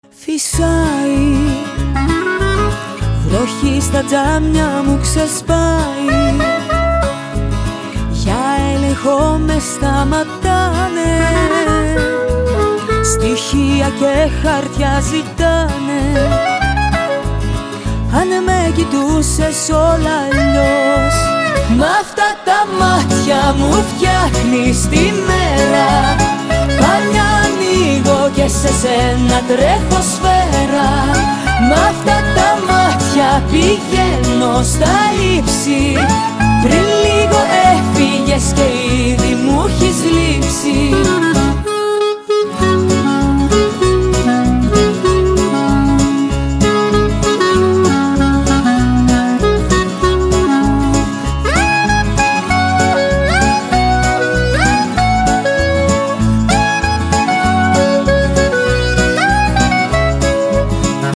• Качество: 256, Stereo
поп
гитара
Саксофон
Blues